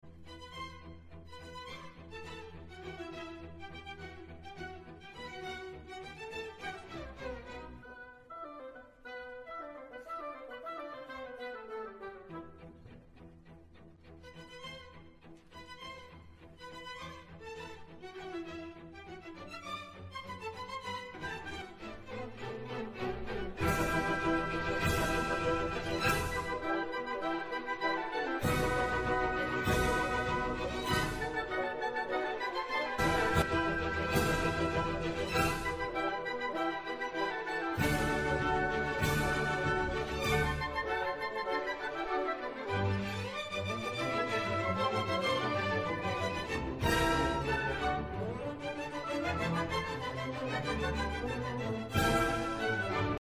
From its sparkling melodies to its breakneck speed, we'll uncover the secrets behind its enduring popularity and why it continues to make audiences laugh centuries later.